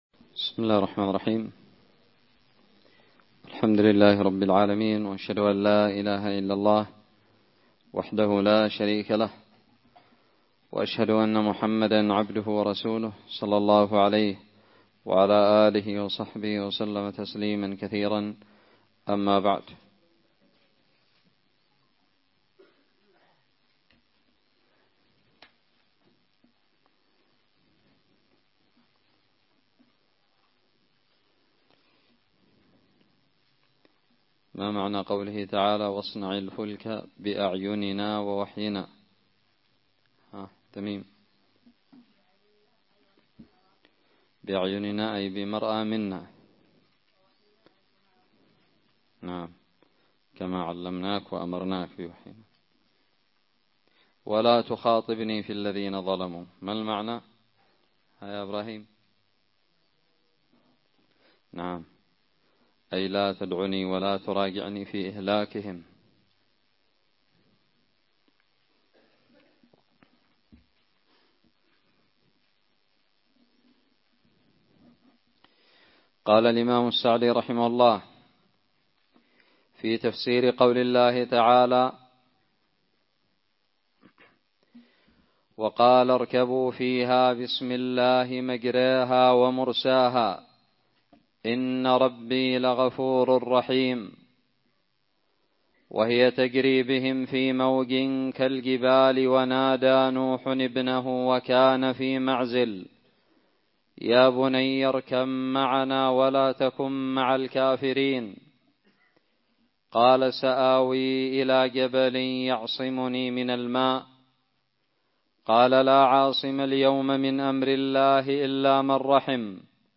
الدرس الرابع عشر من تفسير سورة هود
ألقيت بدار الحديث السلفية للعلوم الشرعية بالضالع